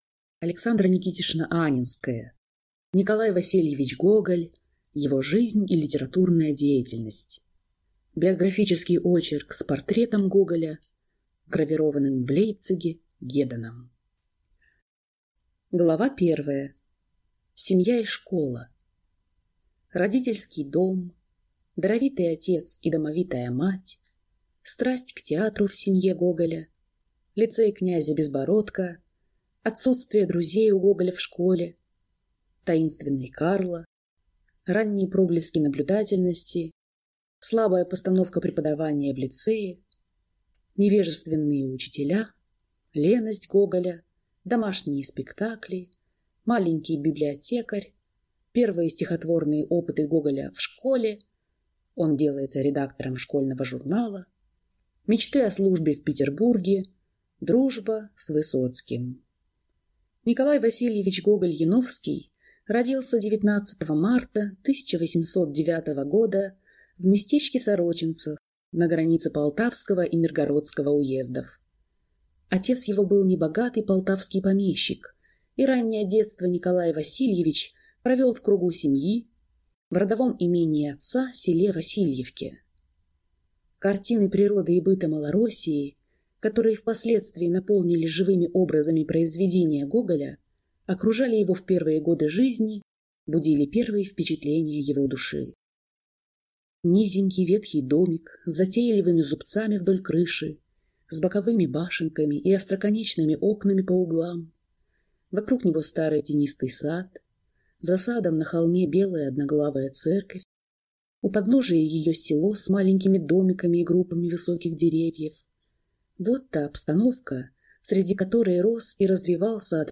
Аудиокнига Гоголь. Его жизнь и литературная деятельность | Библиотека аудиокниг
Прослушать и бесплатно скачать фрагмент аудиокниги